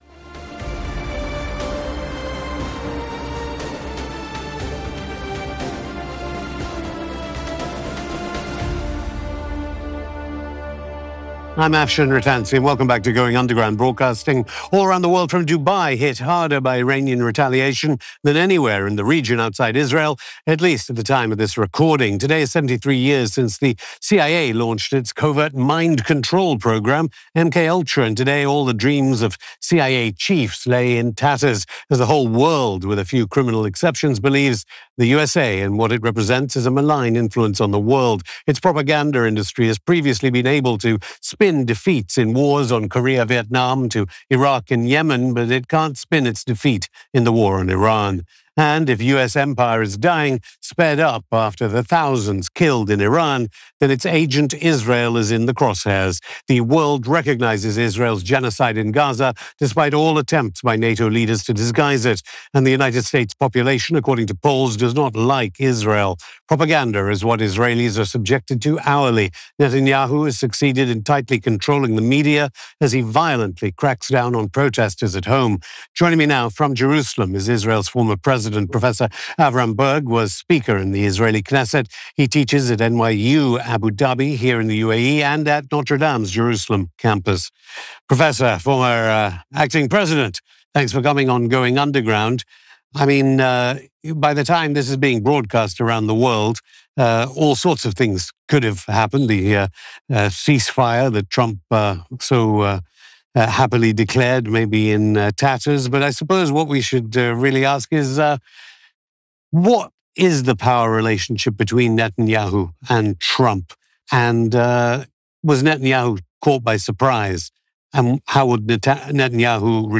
On this episode of Going Underground, we speak to former Israeli Acting President Avraham Burg.